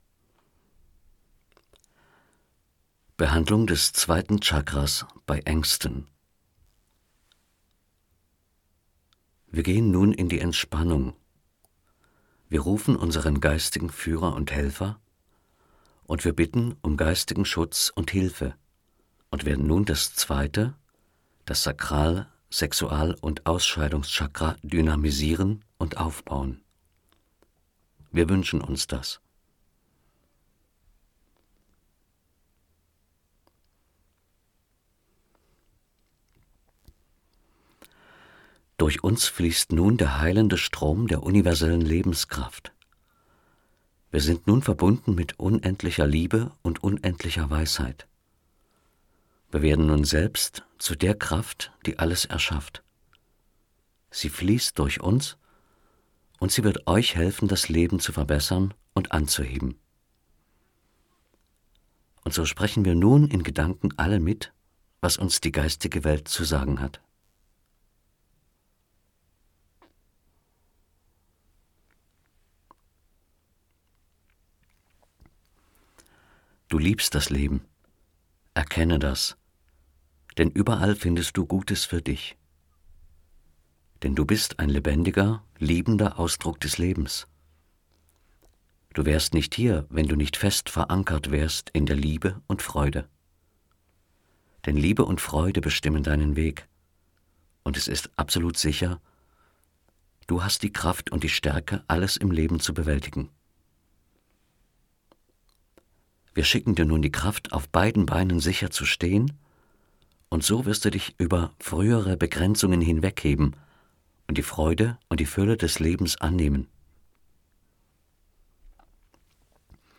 Diese MP3-Datei enhält eine geführte Meditation